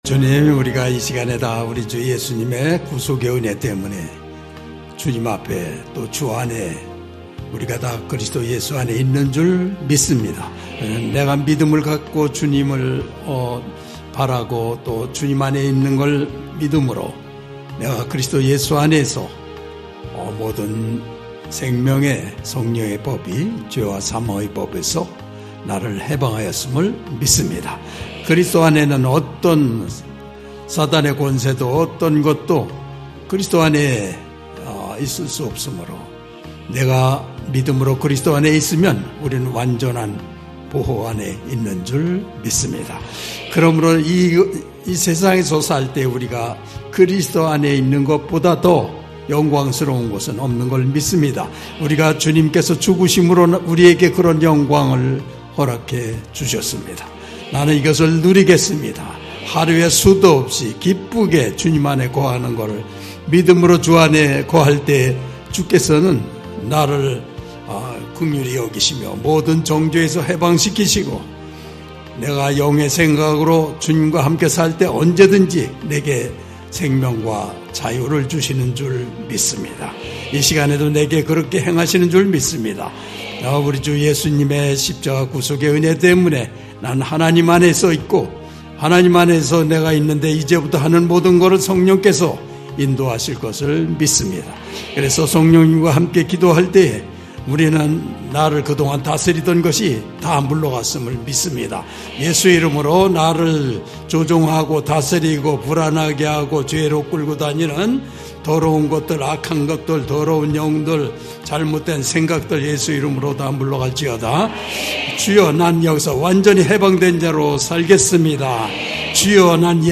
수요가족예배